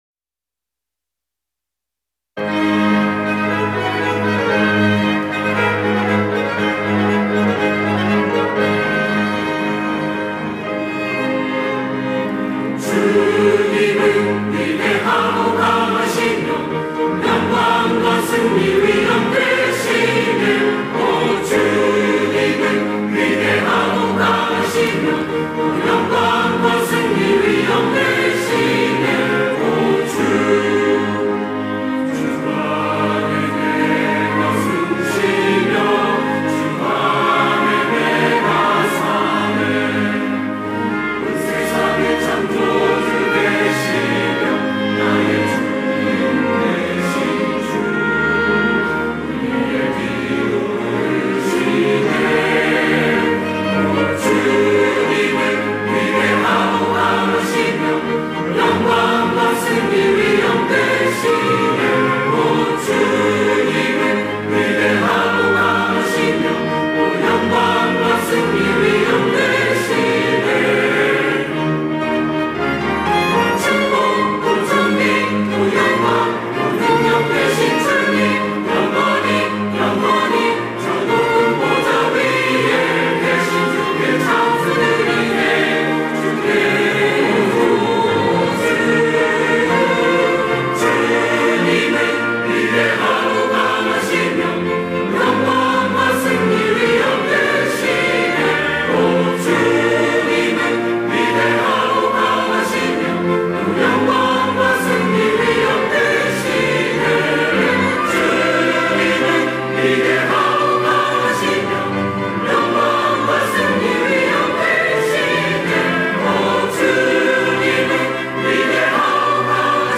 호산나(주일3부) - 위대하신 주님
찬양대